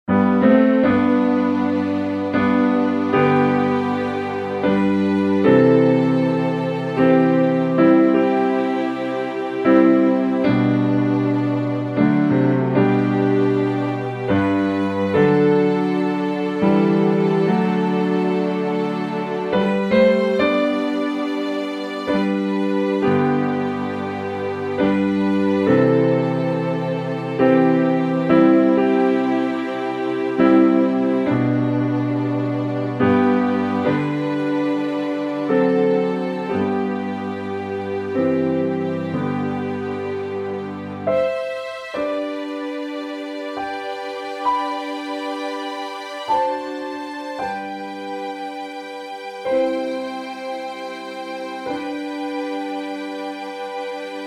Hymn, Sing – AWR - Instrumental Music – Podcast